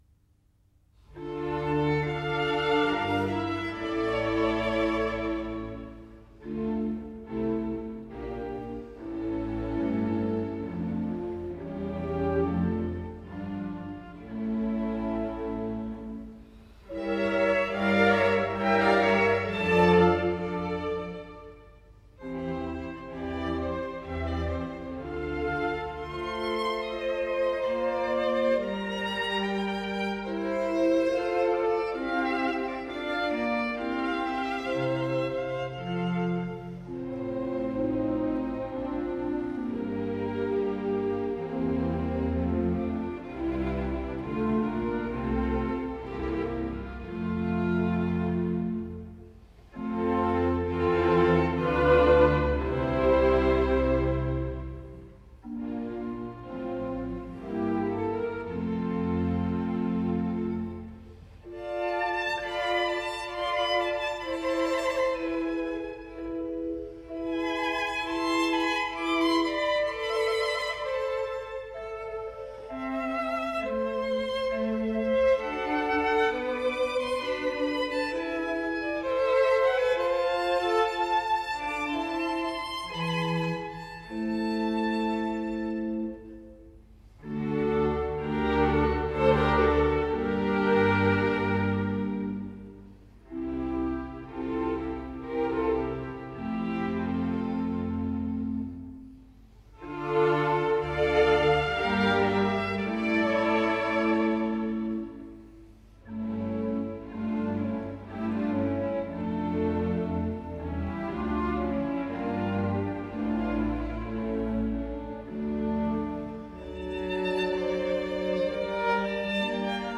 09 - Concerto Grosso Op.6 No.10 in D minor - Air, lento